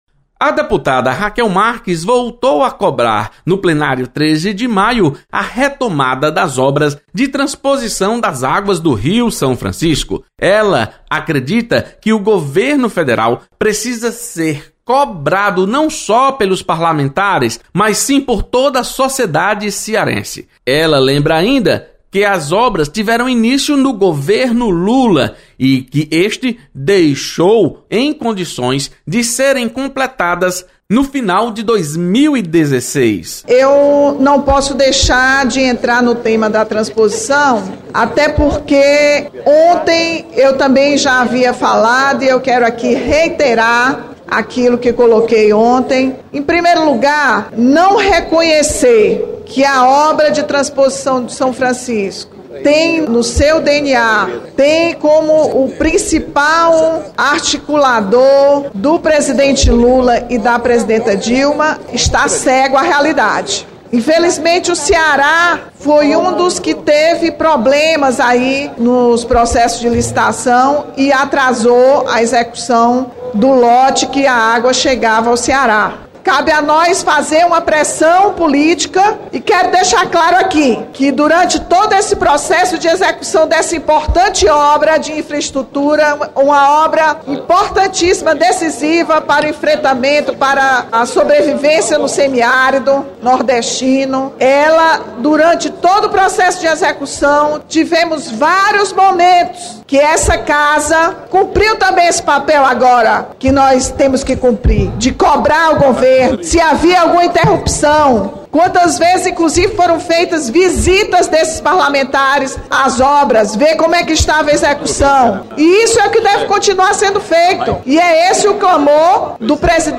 Deputada Rachel Marques ressalta importância da obra da Transposição do São Francisco. Repórter